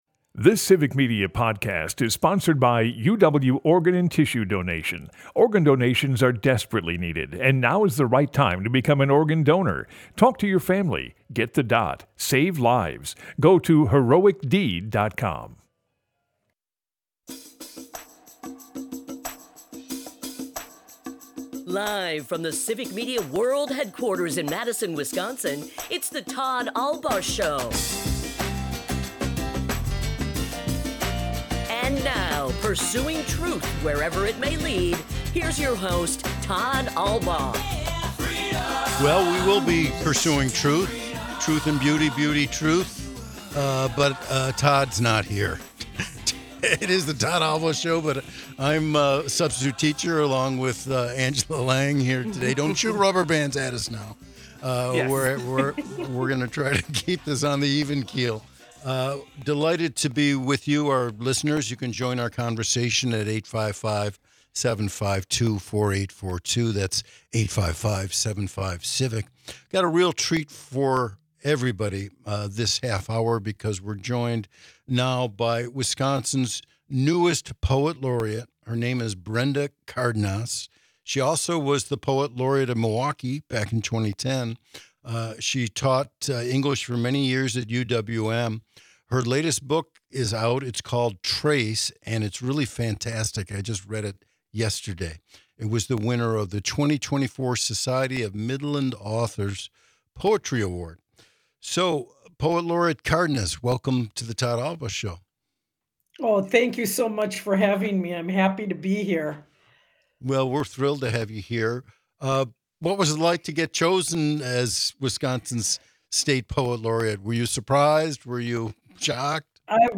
They discuss just what the job of the Poet Laureate is, and her plans for a statewide collaborative poetry and visual art project. We also dip into politics with some poetry she wrote about the first Trump inauguration, and at the bottom of the hour hear her reading of Against Insularity from her new book Trace.